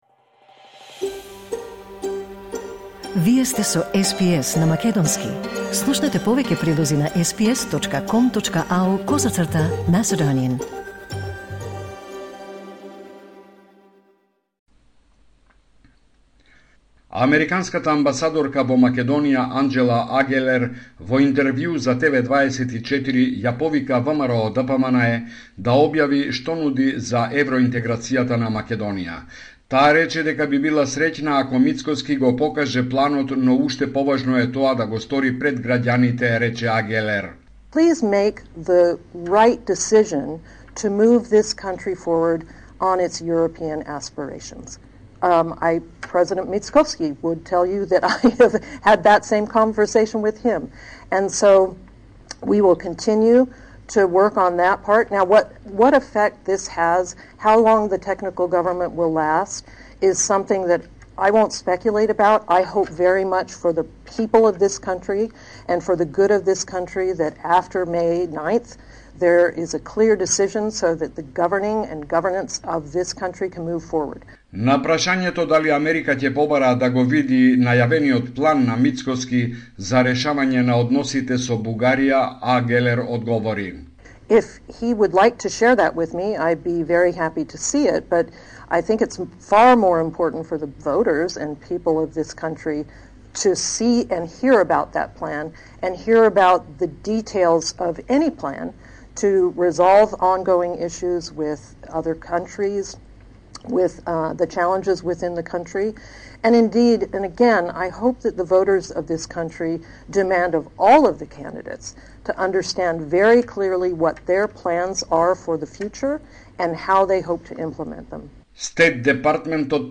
Homeland Report in Macedonian 2 February 2024